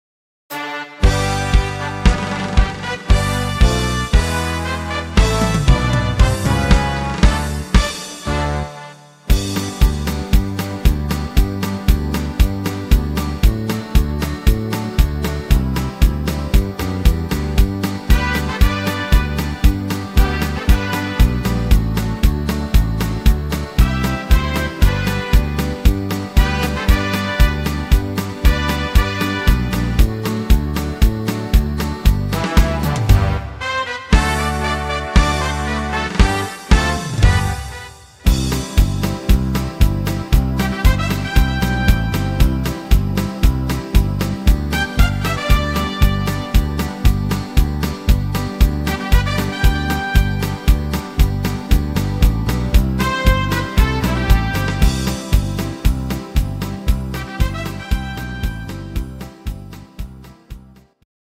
instrumental Akkordeon
Rhythmus  Marsch
Art  Deutsch, Instrumental Akkordeon